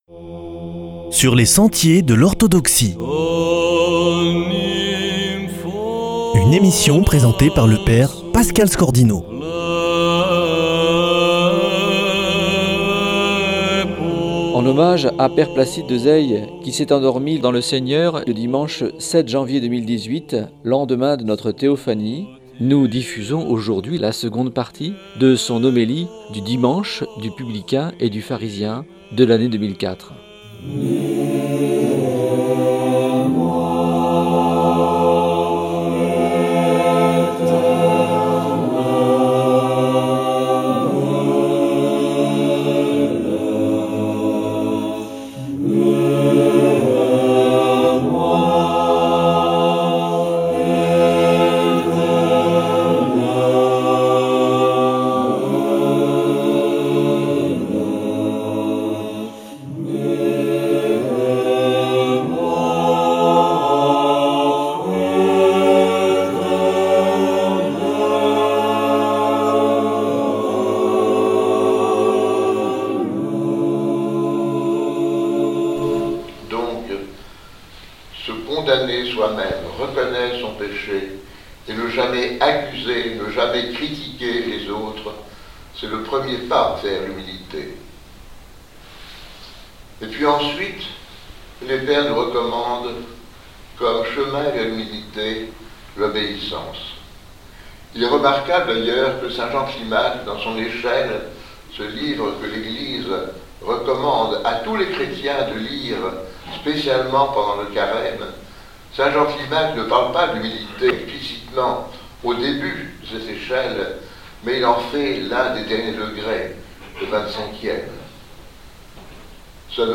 homélie dimanche du Publicain et du Pharisien 2004 / 2nde partie